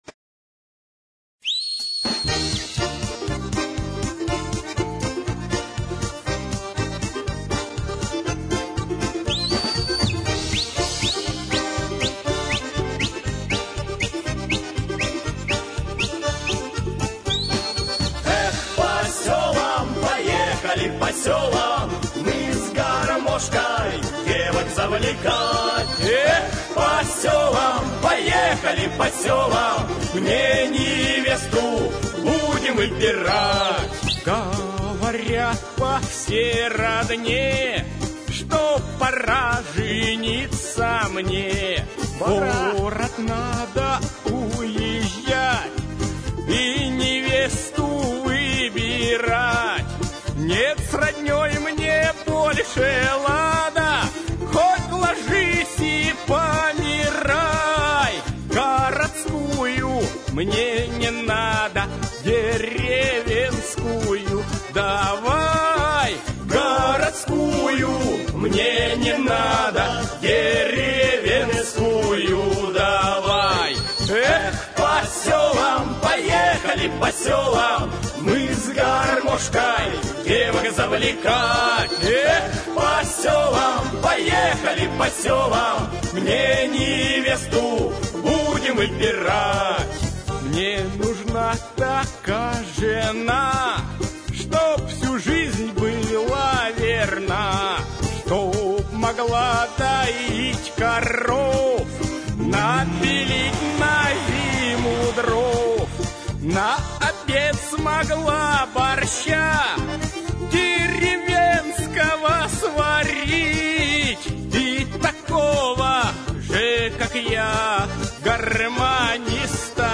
Всі мінусовки жанру Pop-Folk
Плюсовий запис
Весело і гарно звучить!